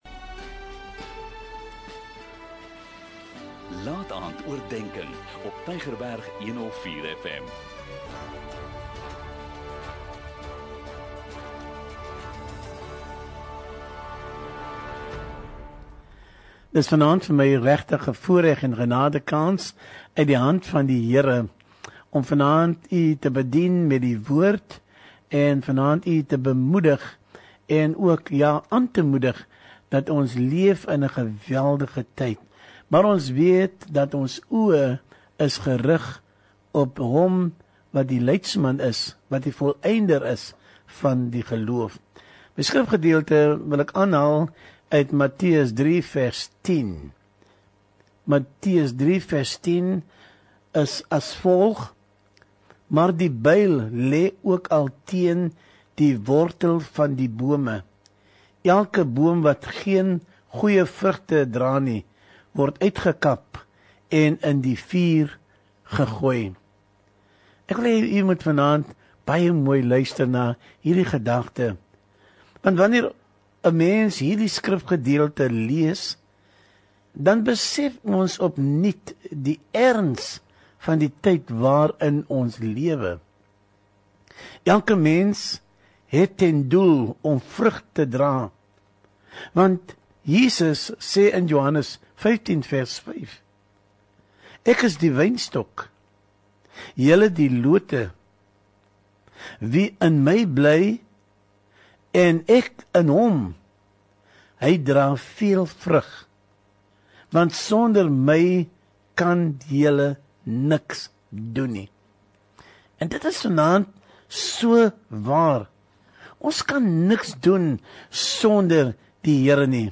'n Kort bemoedigende boodskap, elke Sondagaand om 20:45, aangebied deur verskeie predikers